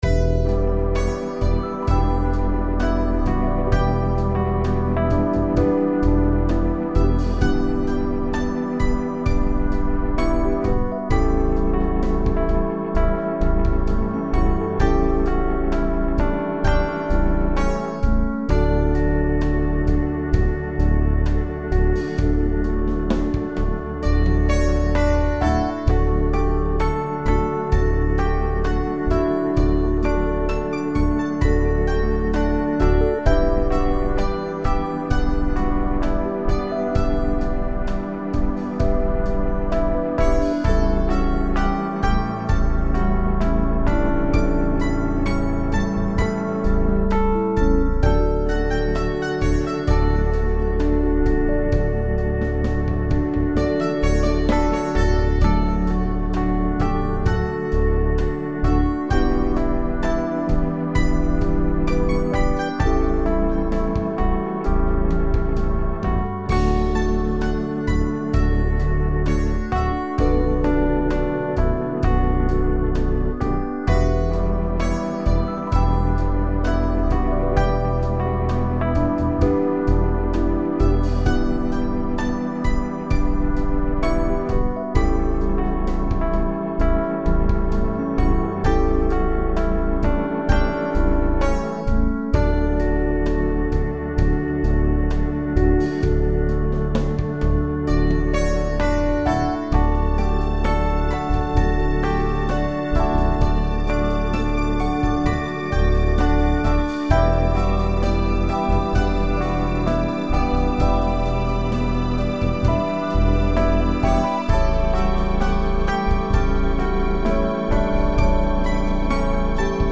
[MP3 Instrumental]